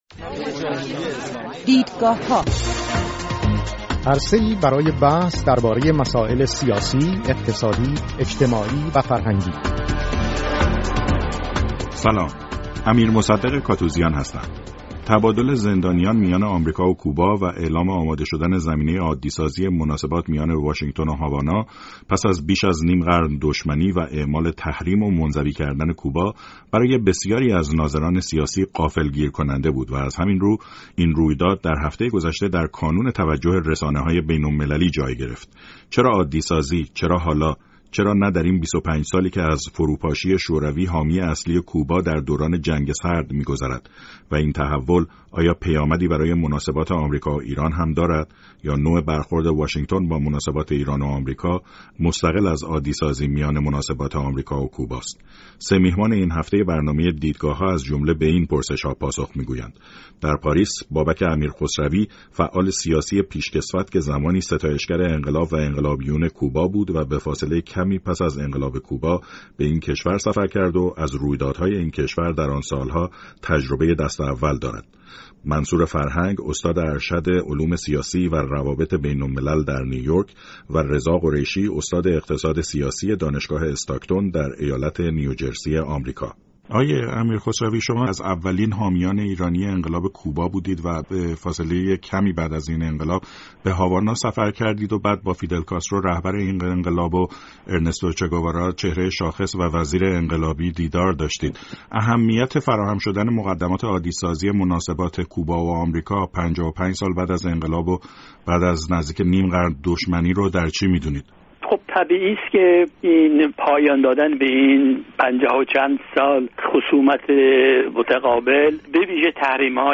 و این تحول آیا پیامدی برای مناسبات آمریکا و ایران هم دارد؟ سه میهمان این هفته برنامه دیدگاه‌ها از جمله به این پرسش‌ها پاسخ می‌گویند.